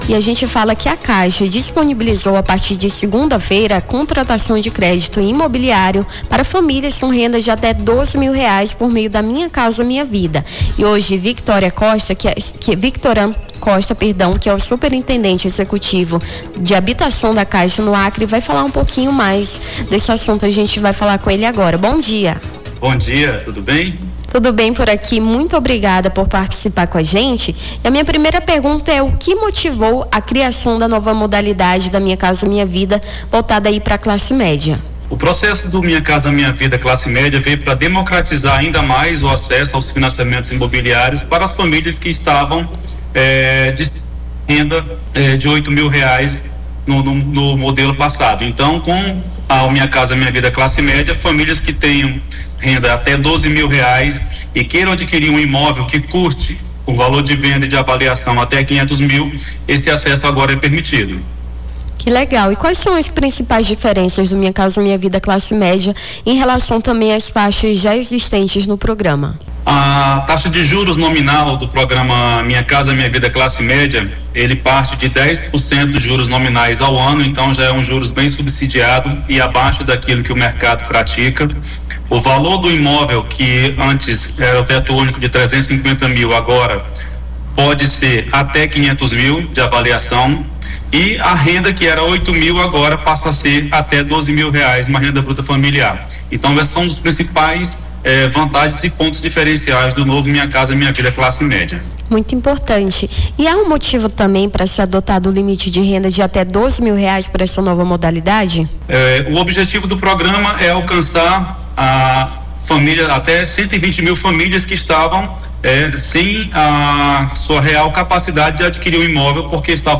Nome do Artista - CENSURA - ENTREVISTA MINHA CASA, MINHA VIDA (13-05-25).mp3